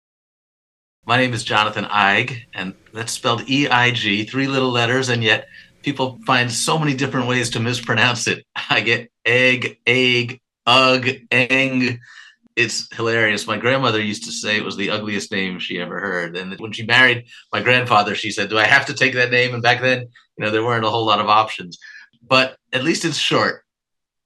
Audio Name Pronunciation
A recording introducing and pronouncing Jonathan Eig.
Educator Note: This primary source recording gives insight into a book creator and is not directly tied to a specific book.